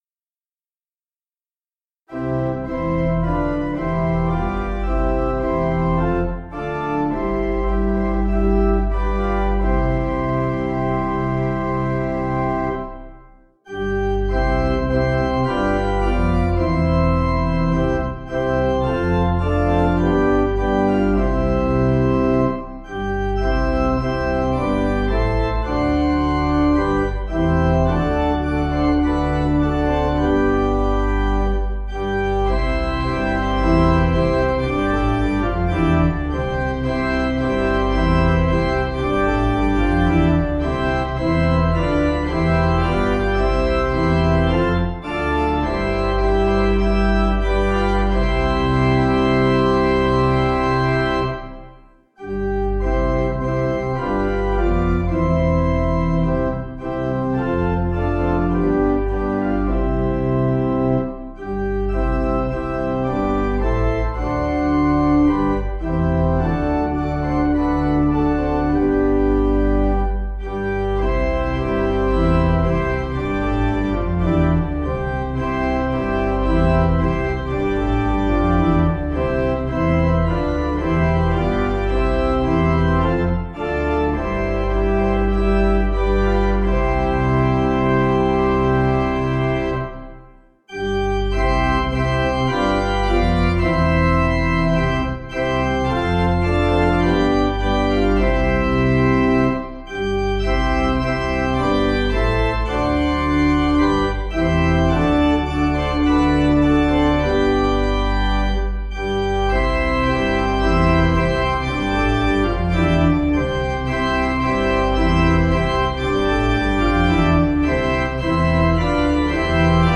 7.6.7.6.D